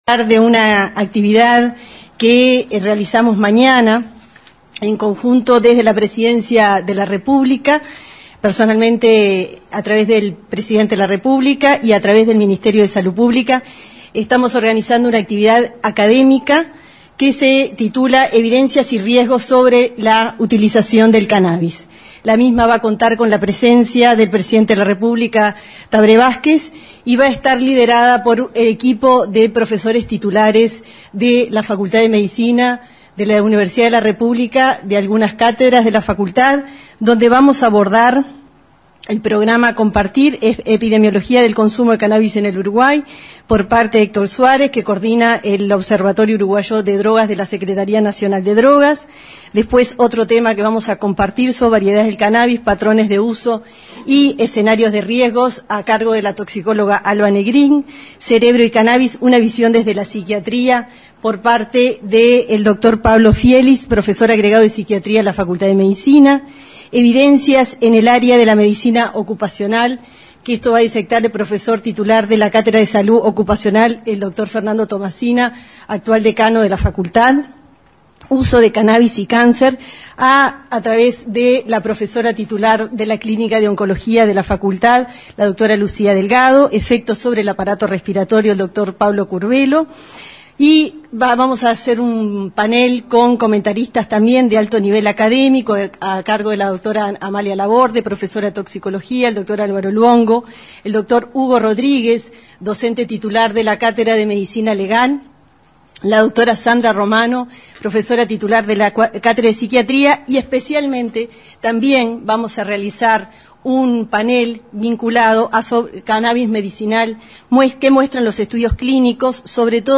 Según explicó la jerarca en conferencia de prensa, luego de un nuevo Consejo de Ministros, un panel expondrá los resultados de estudios clínicos que recomiendan el uso de la marihuana medicinal en ciertos casos, entendiendo que hay evidencias de mejoras en la epilepcia refractaria en niños, en la esclerosis múltiple, en los cuidados paleativos y en enfermedades de la piel.